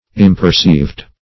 Imperceived \Im`per*ceived"\